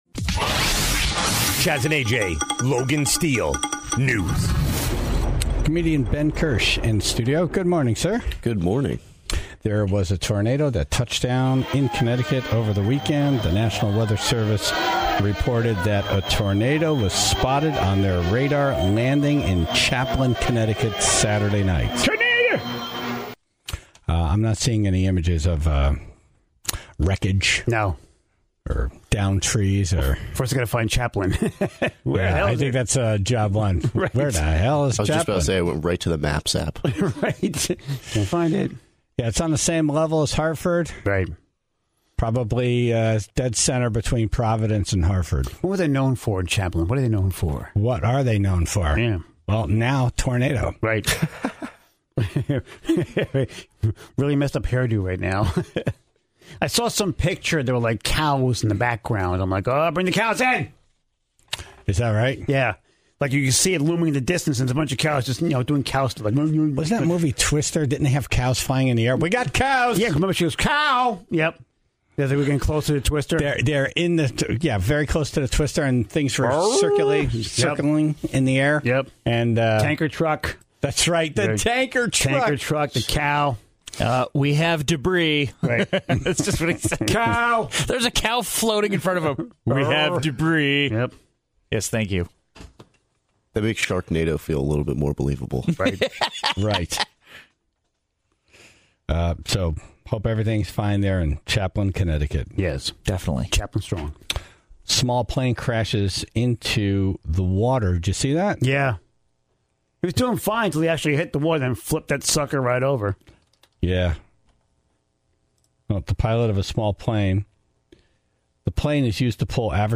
Plus, audio of some excited eyewitnesses to a plane crash on the beach in New Hampshire.